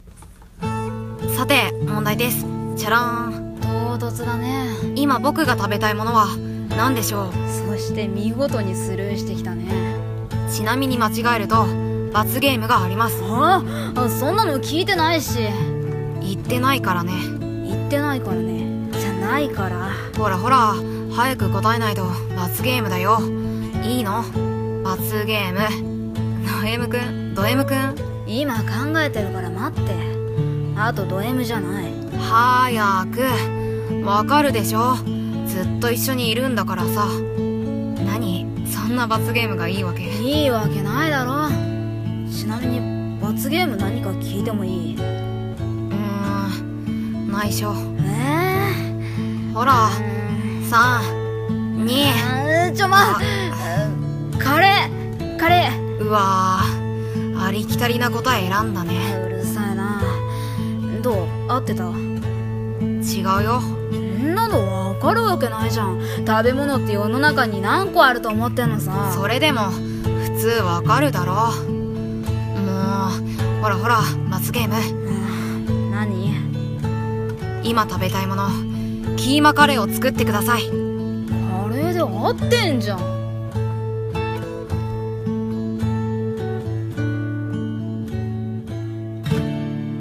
【声劇】「食べたいもの」